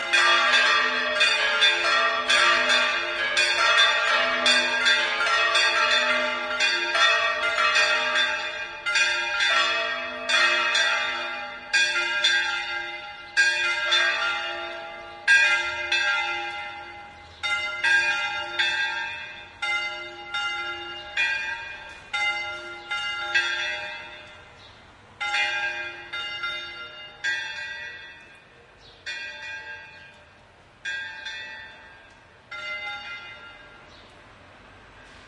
描述：La Magdalena教区（西班牙塞维利亚）的钟声响起。Edirol R09和内部话筒
Tag: 市南西班牙 教堂的氛围 现场录音 敲打 铃铛